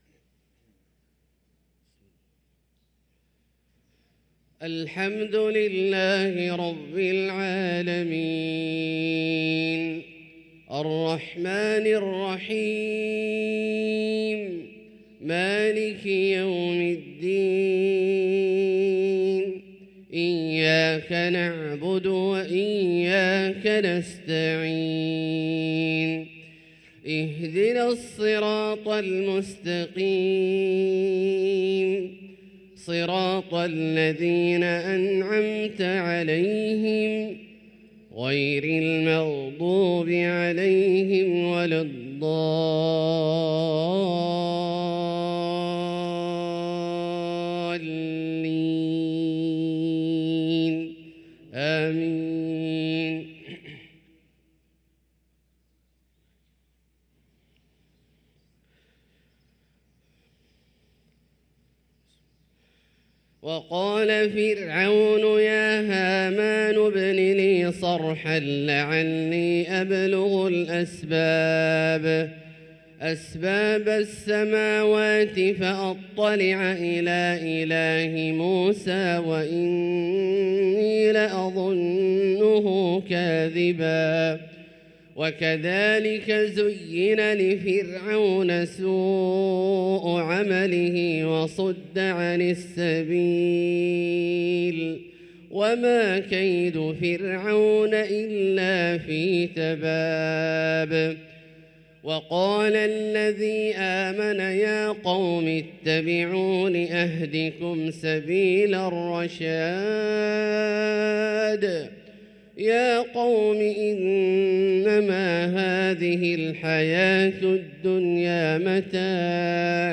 صلاة الفجر للقارئ عبدالله الجهني 27 ربيع الأول 1445 هـ